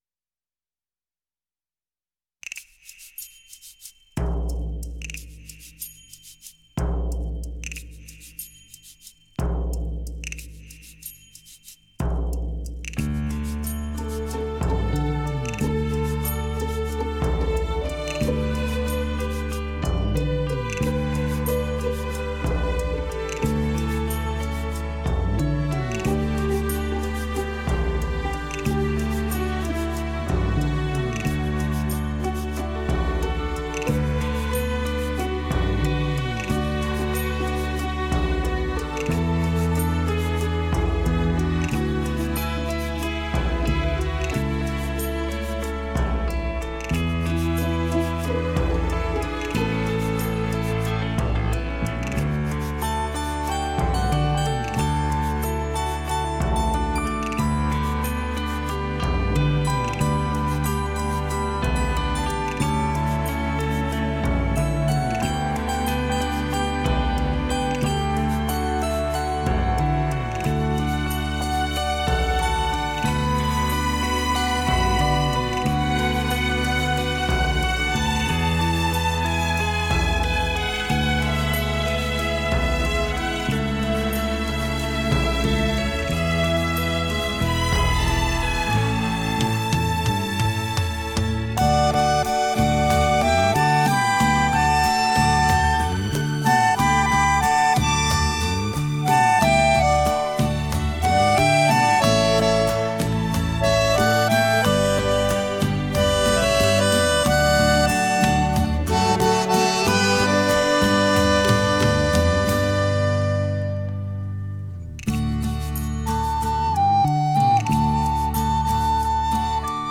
在于结构简单，节奏明快，旋律动听，具有亲切明了的内涵，